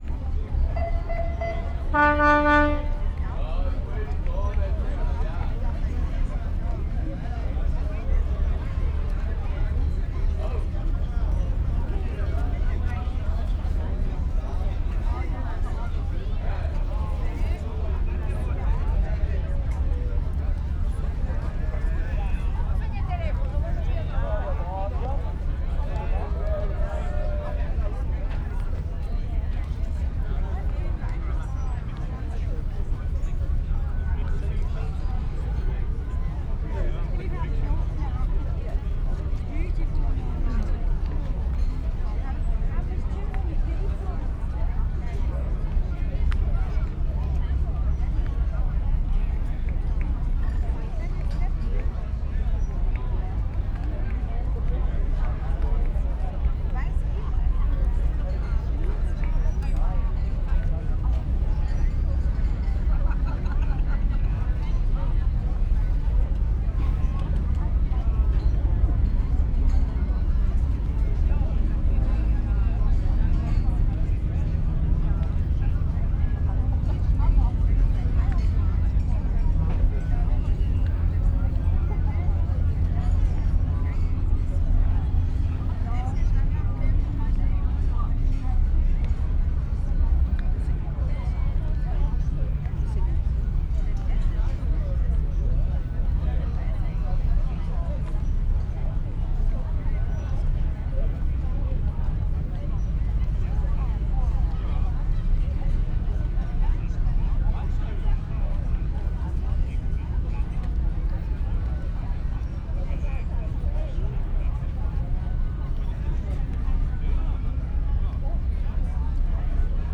kurtjelzes_hangulat_szentmarkter_sds05.06.WAV